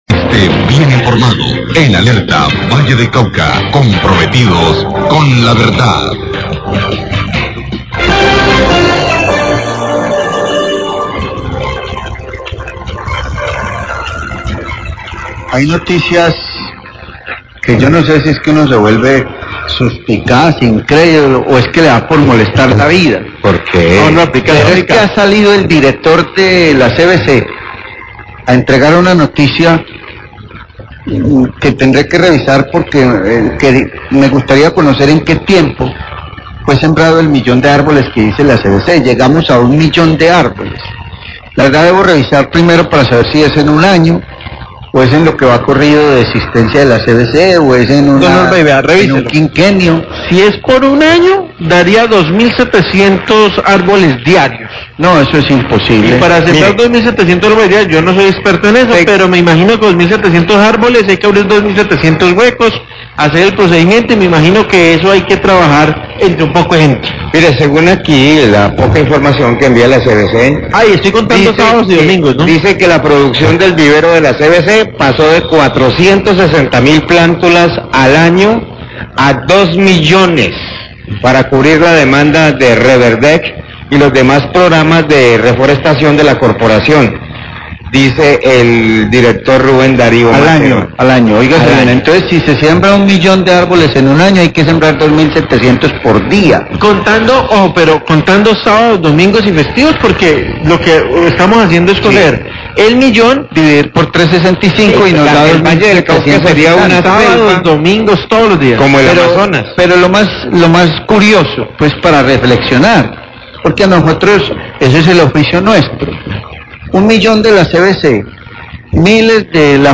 Radio
Periodistas de Radio Tuluá dudan, critican y hasta burlan, de la información entregada por el director de la CVC, de la siembra de un millón de árboles como parte del proyecto ReverdeC.